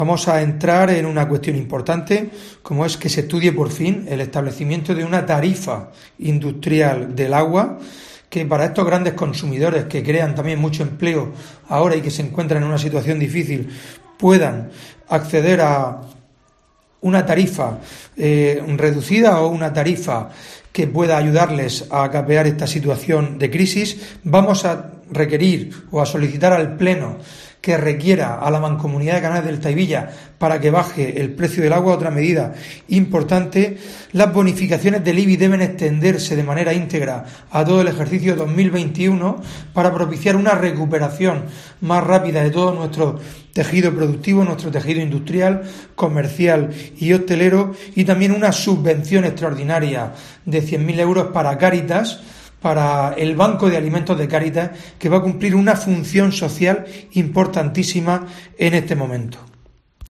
Fulgencio Gil, portavoz del PP sobre nuevas propuestas